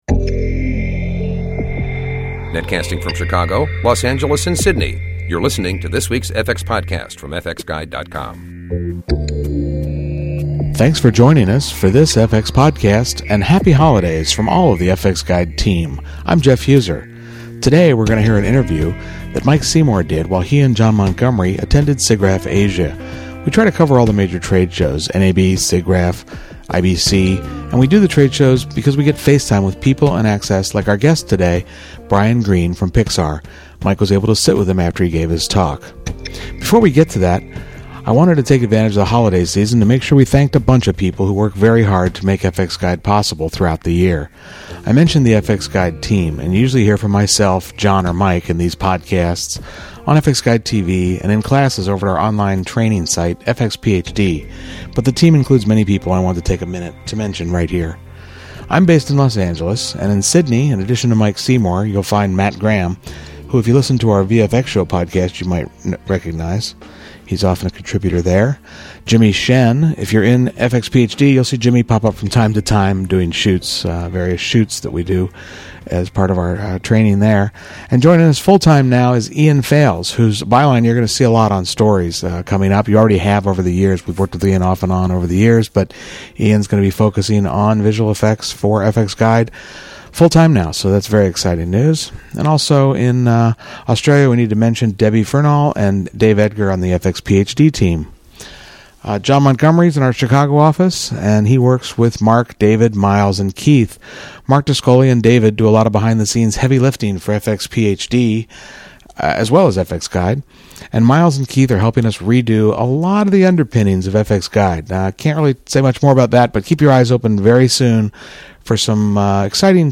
at Siggraph Asia to talk about Toy Story 3